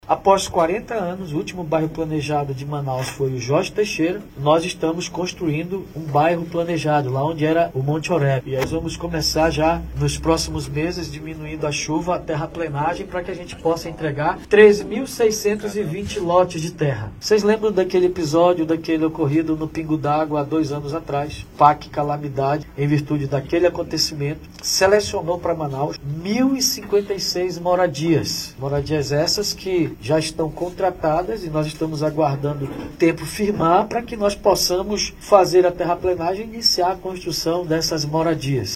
Nos últimos 40 anos, a cidade de Manaus teve 60% de toda a sua área ocupada de forma indevida, e a falta de políticas públicas faz com que famílias morem em áreas de risco, totalizando 52 mil casas localizadas em terrenos de risco, como explica o prefeito David Almeida.
SONORA-1-PREFEITO.mp3